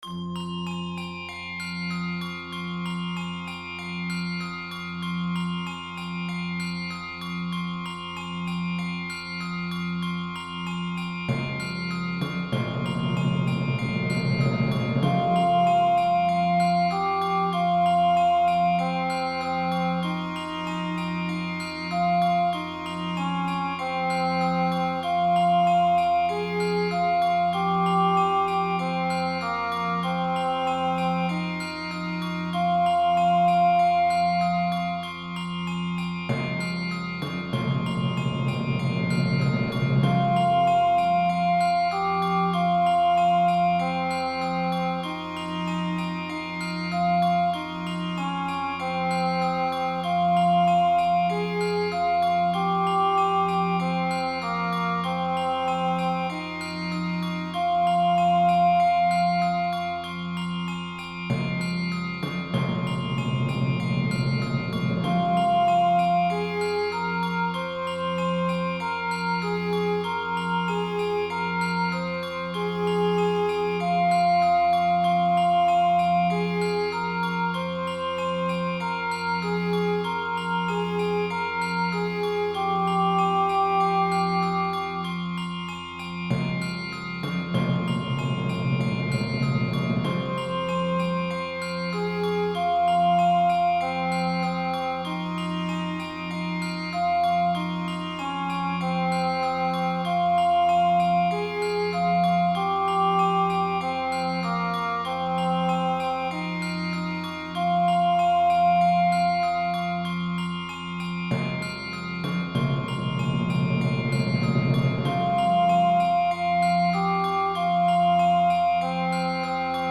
Choir Unison, Organ/Organ Accompaniment, Percussion
Voicing/Instrumentation: Choir Unison , Organ/Organ Accompaniment , Percussion